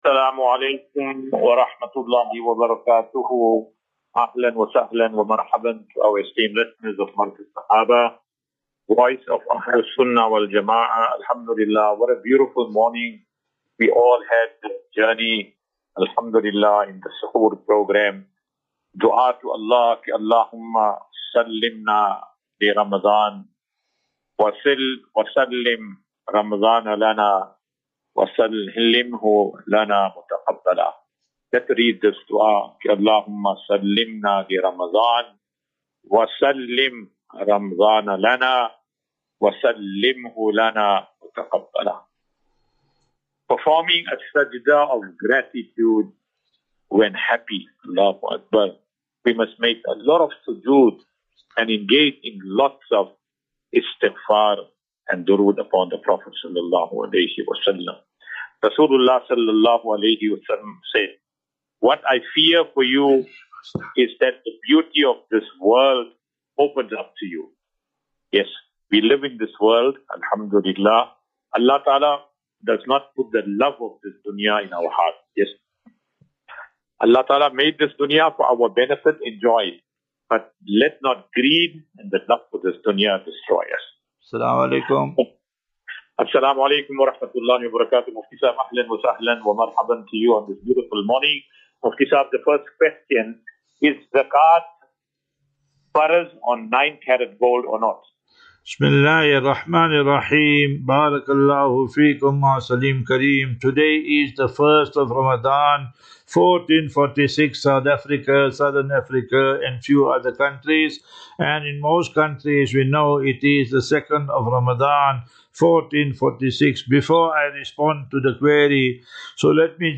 View Promo Continue Install As Safinatu Ilal Jannah Naseeha and Q and A 2 Mar 02 March 2025.